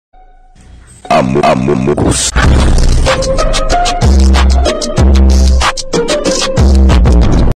Shotgun Reload Sound Effect Button | Soundboard Unblocked